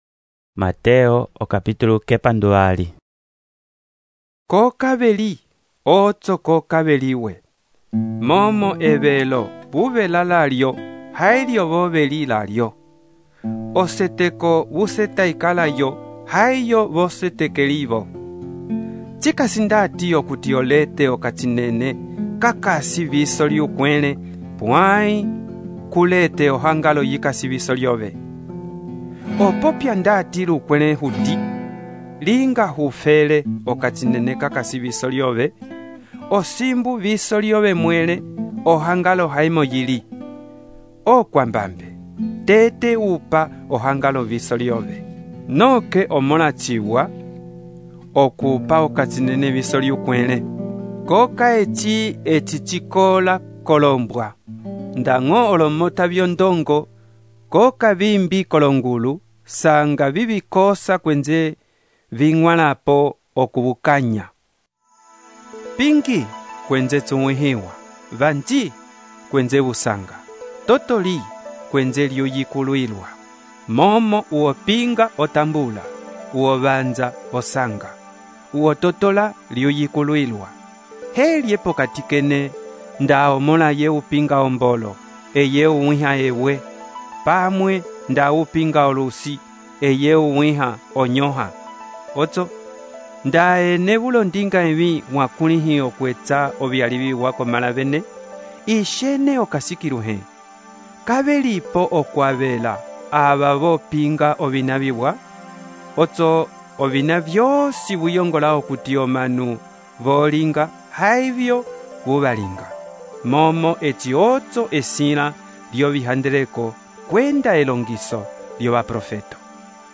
texto e narração , Mateus, capítulo 7